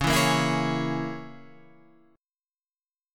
C# Minor 6th